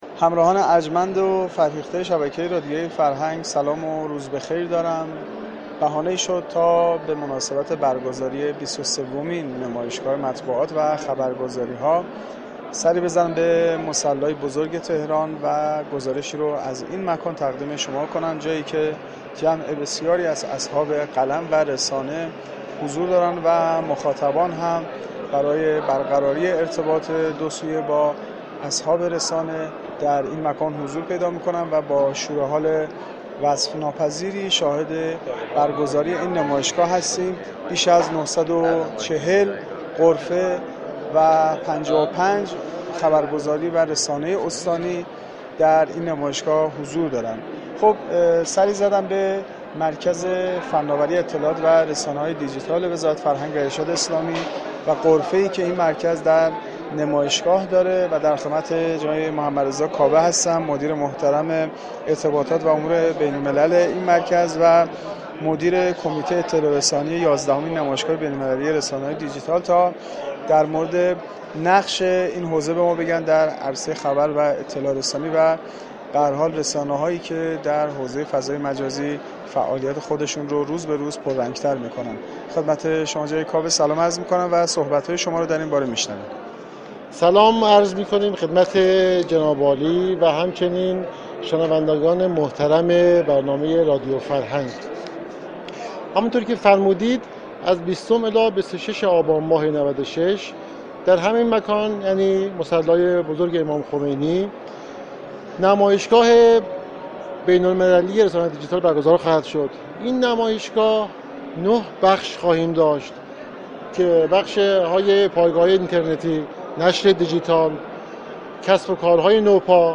در گفتگو با گزارشگر رادیو فرهنگ